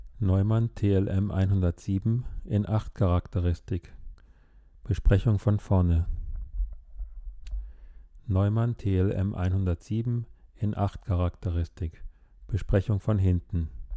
Test-Setting: Einfach Mikro in die Hand genommen und rein gesprochen, dann das Mikro um die eigene Achse gedreht. Dabei ist mir aufgefallen, dass bei der ACHT-Charakteristik der Klang bei 180° völlig anders ist als bei Einsprechung von vorne (0°). Im Wesentlichen sind bei 180° die tiefen Frequenzen deutlich angesenkt, dafür treten die Mitten mehr hervor.